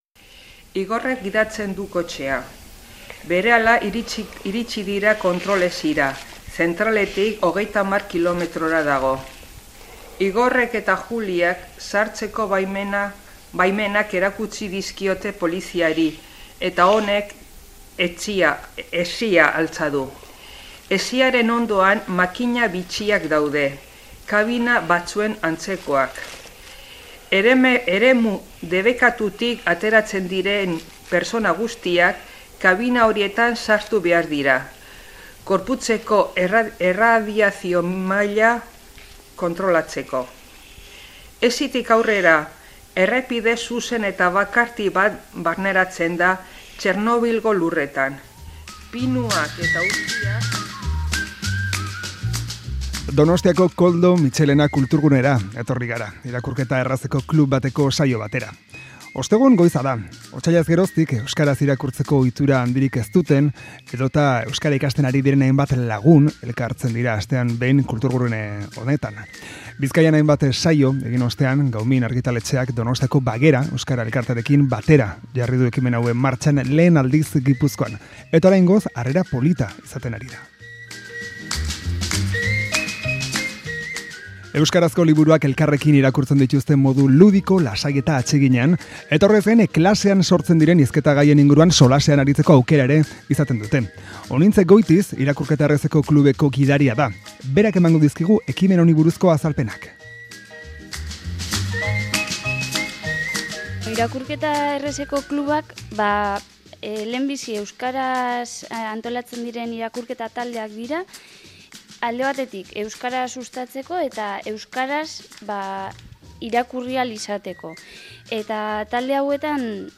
Amarauna irakurketa errazeko saio batean izan da Donostiako Koldo Mitxelena kulturgunean. Hementxe duzue, bertan entzundako ahotsen bilduma Whatsapp Whatsapp twitt telegram Bidali Esteka kopiatu nahieran